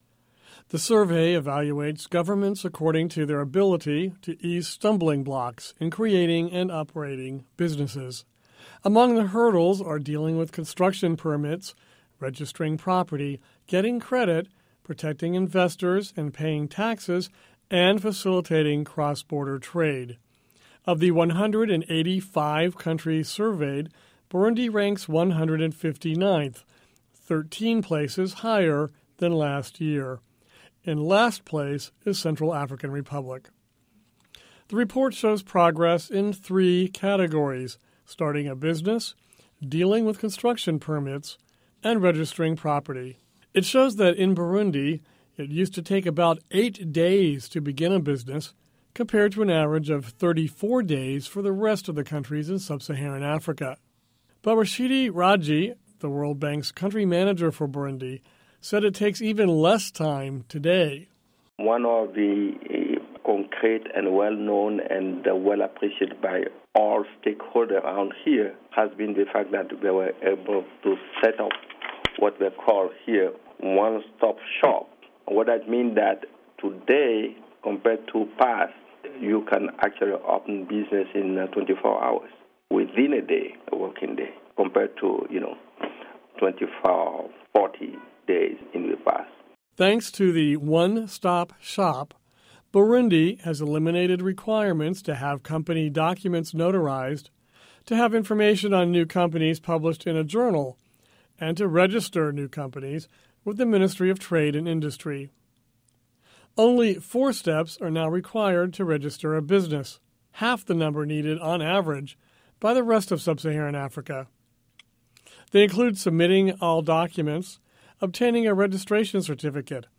Listen to report on Burundi